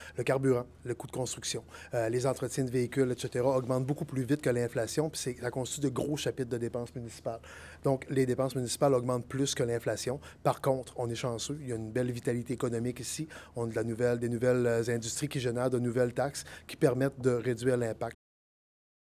Le maire Daniel Côté :